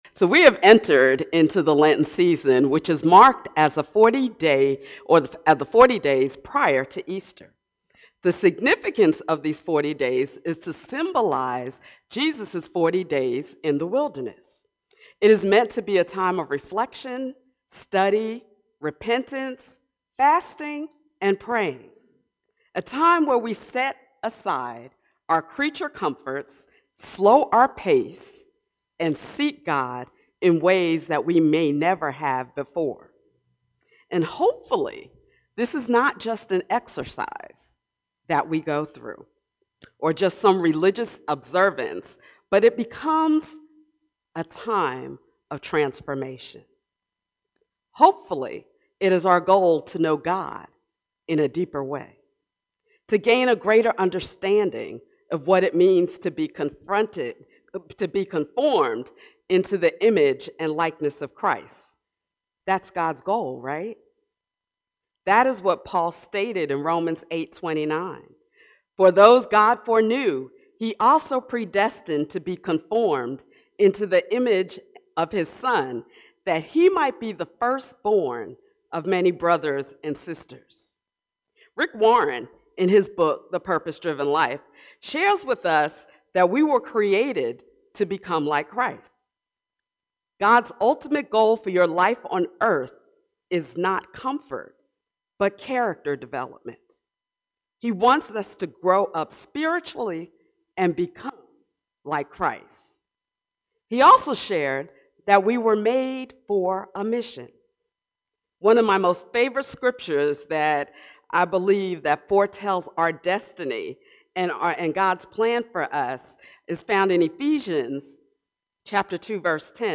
This Sunday, as we continue our journey through the season of Lent, we welcome guest preacher